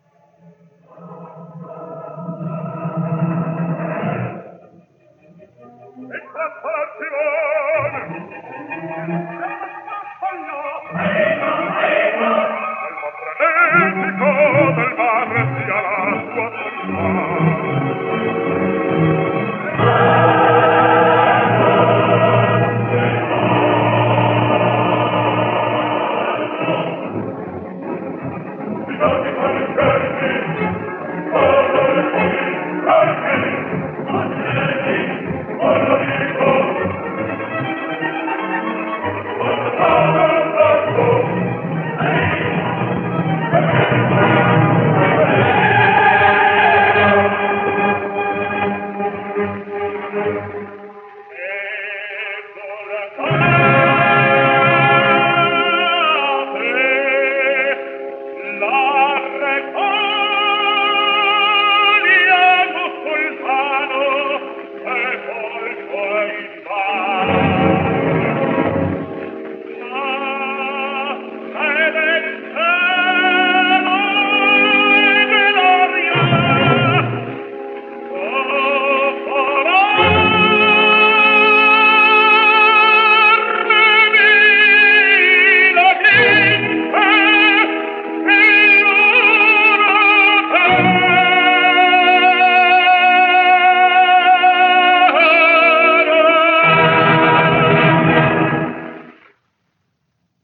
Giovanni Zenatello | Italian Tenor | 1876 - 1949 | Tenor History
So, we are able to hear Zenatello’s Ensultate, straight from a national performance from Covent Garden in 1926.